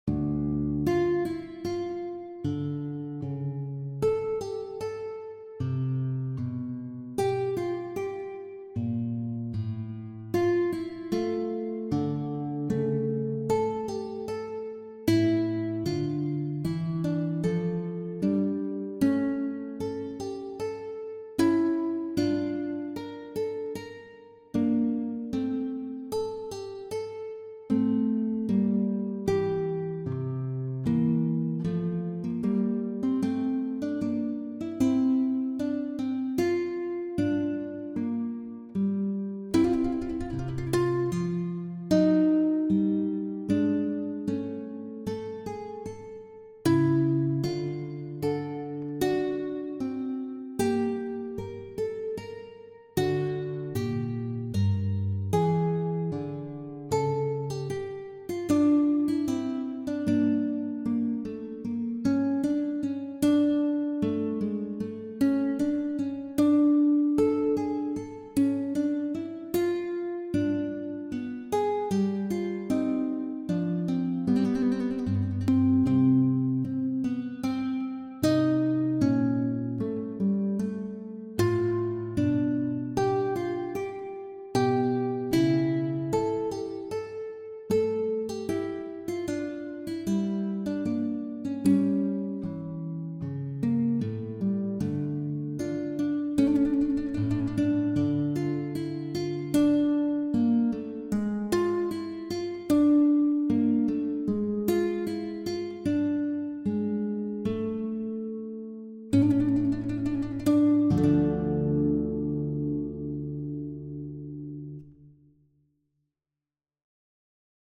Concerto-en-re-majeur-2eme-mouvement-Andante.mp3